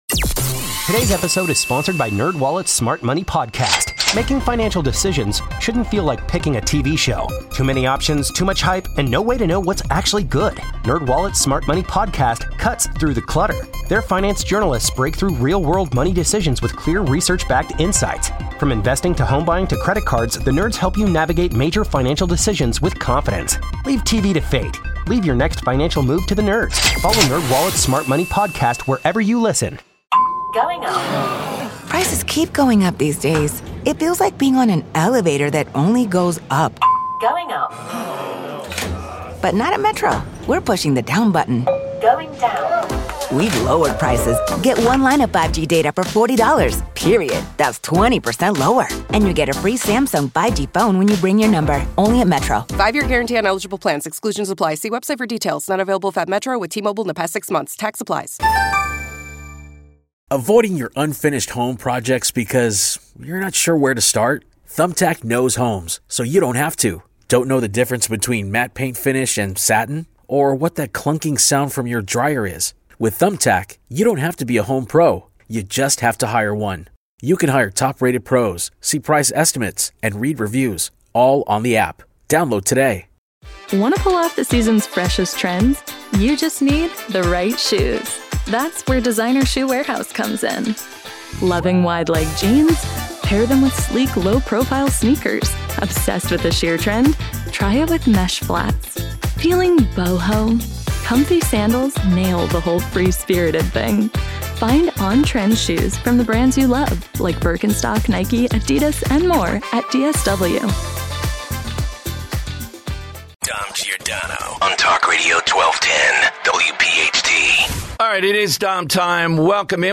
This hour, Rep. Lee Zeldin hops on the show to break down the upcoming debate between Biden and Trump.